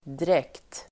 Uttal: [drek:t]